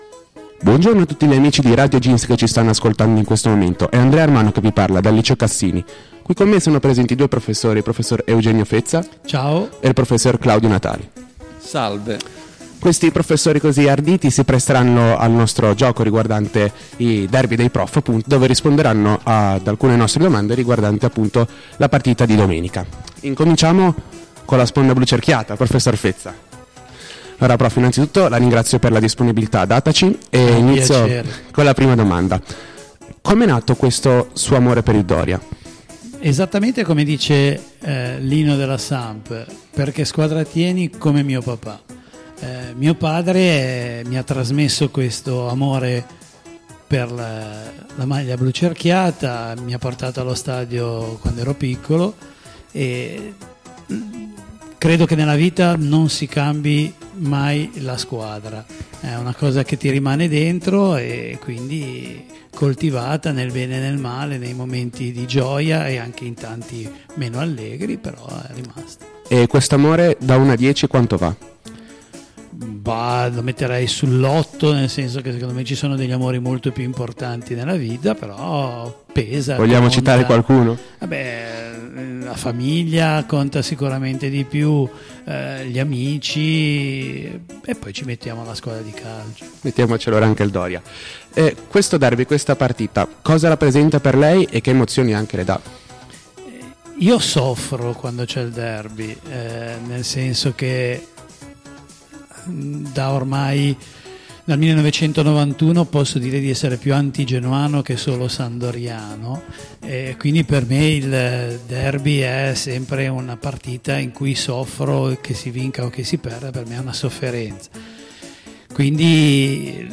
Intervista
Ancora una volta, dopo una serie di domande sul rapporto dei docenti con la squadra del cuore ed il Derby della Lanterna, una sfida in un "botta e risposta" serrato.